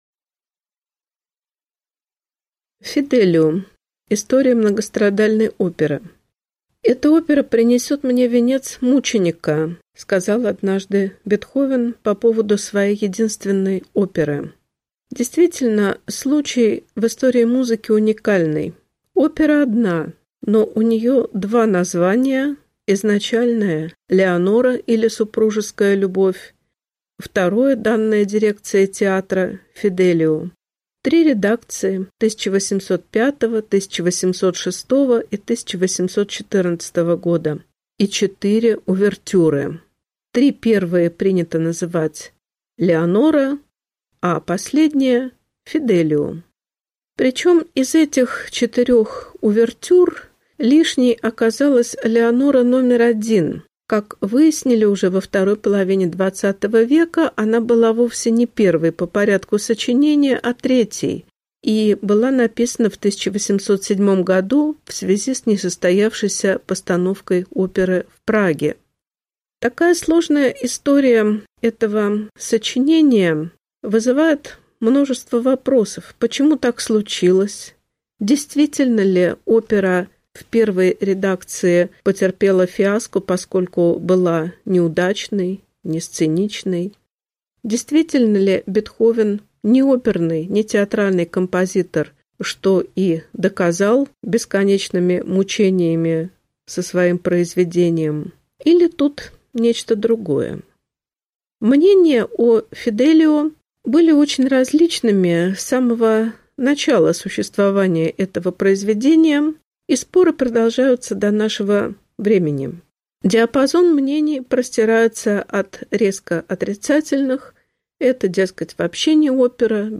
Аудиокнига Лекция «„Фиделио“: история многострадальной оперы» | Библиотека аудиокниг